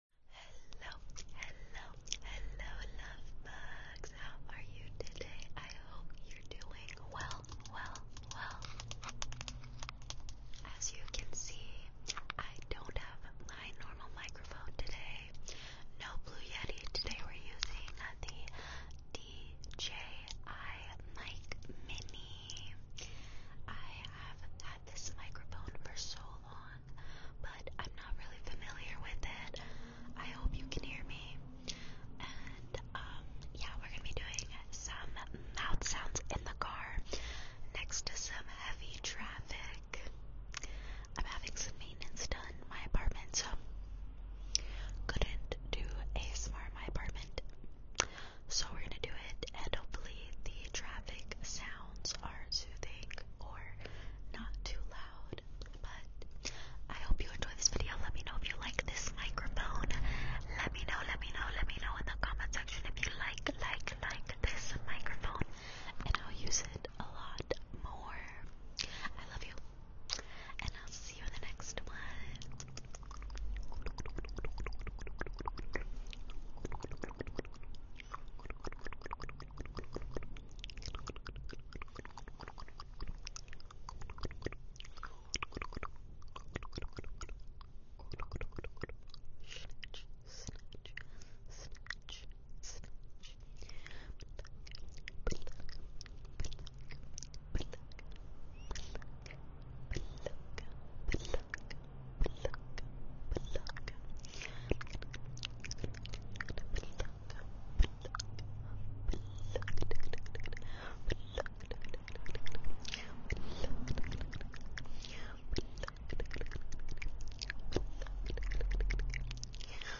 ASMR | Unpredictable Mouth Sounds In My Car 👄 DJI MINI MIC